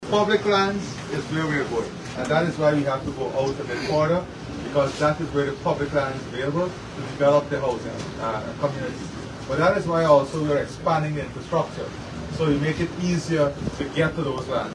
During his interaction with residents near the Bartica Stelling, President Ali committed to several interventions aimed at enhancing the community’s livelihoods.